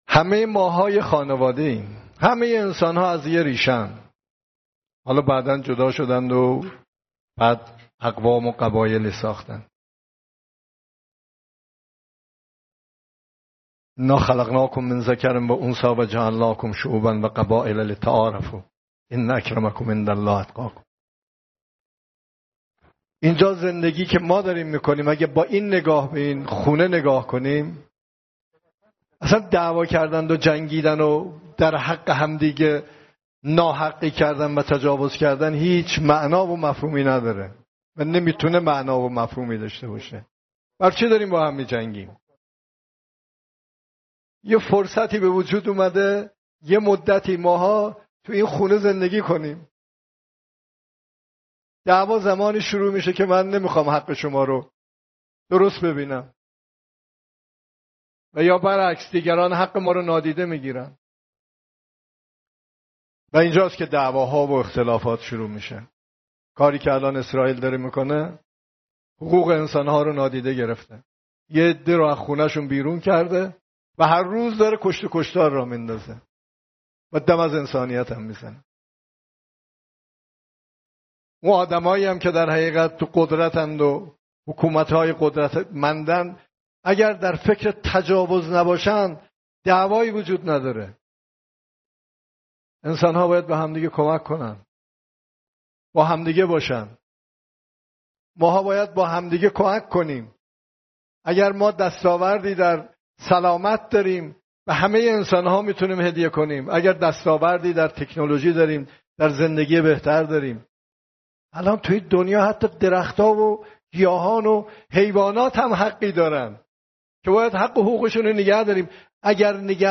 سخنان رئیس جمهور در نشست مجمع گفت‌وگوی تهران